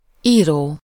Ääntäminen
IPA: /ˈiːroː/